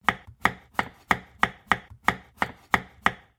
7. Нарезка моркови на деревянной доске